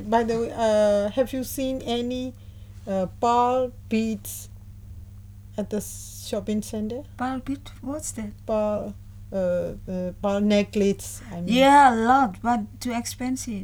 FIndon : female from Java, Indonesia FMyan : female from Myanmar
FIndon : bal bead what’s that FMyan : pearl er er pearl necklace FIndon : yeah a lot but too expensive Intended Word: pearl Heard as: bal Discussion: Two features of the pronunciation contribute to this misunderstanding: there is no aspiration on the initial /p/; and the vowel is an open vowel [ɑ:] rather than the expected mid-central vowel [ɜ:].